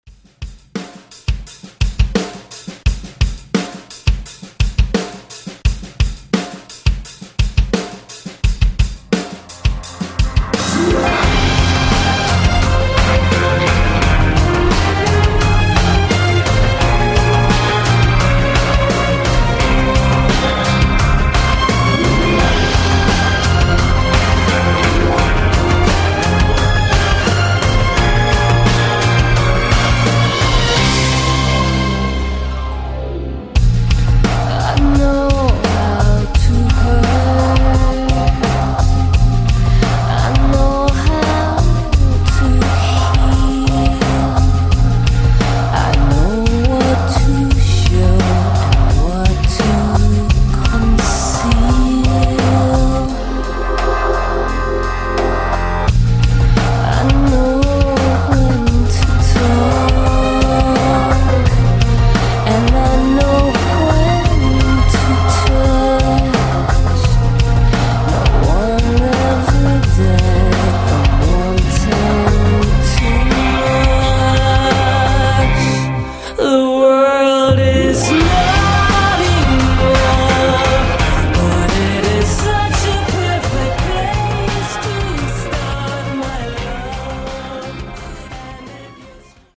Genres: GERMAN MUSIC , HIPHOP , RE-DRUM
Dirty BPM: 94 Time